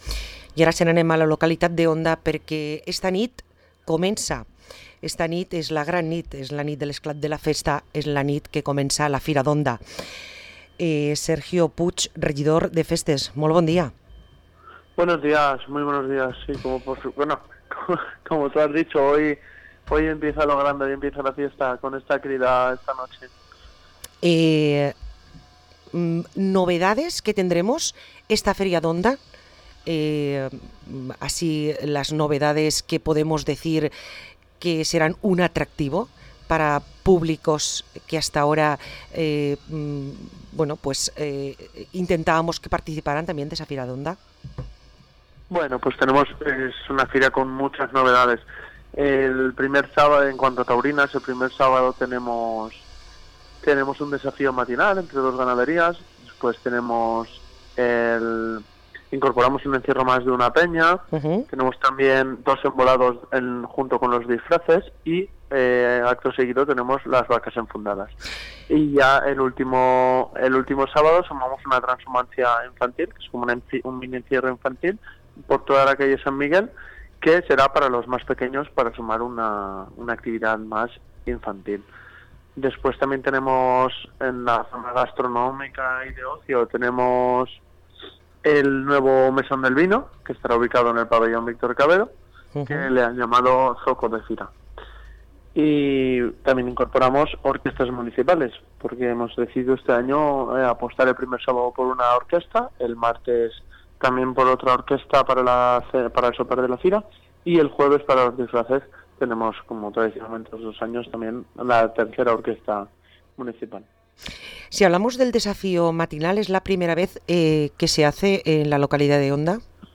Parlem amb Sergio Puig, regidor de festes i tradicions a la localitat d´Onda